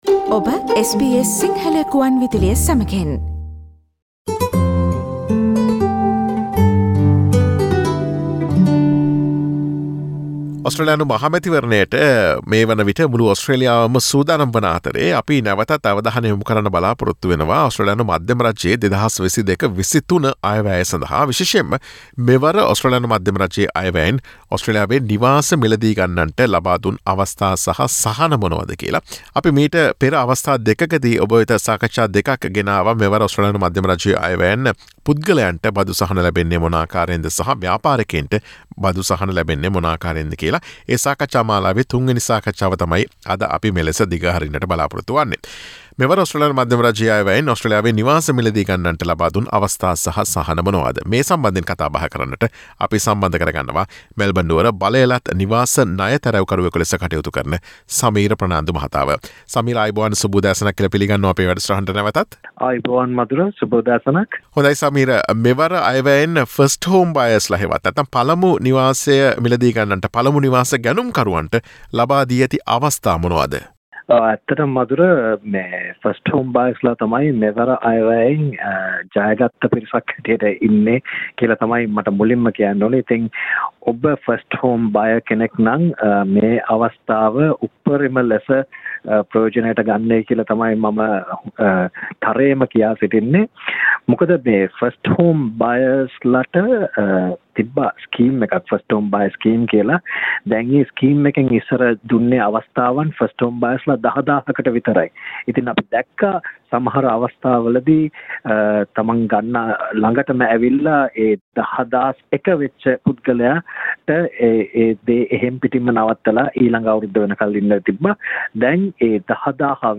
Listen to SBS Sinhala Radio's discussion on opportunities and benefits provided to home buyers in Australia by the Australian budget 2022-23.